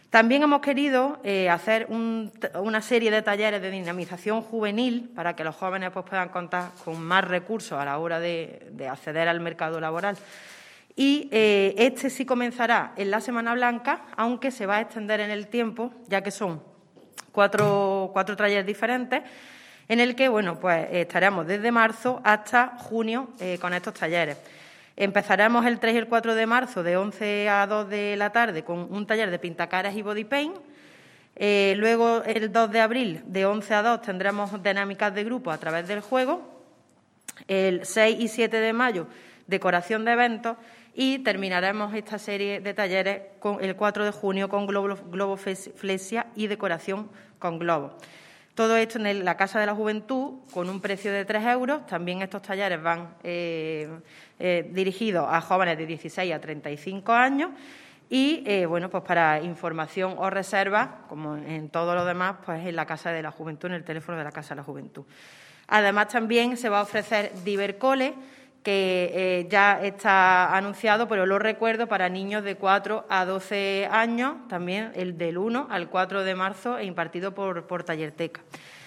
La teniente de alcalde delegada de Juventud, Elena Melero, ha presentado dicha programación en rueda de prensa en la mañana de hoy junto a representantes del colectivo “La Cara B”, promotores de un evento musical al que se dará cabida.
Cortes de voz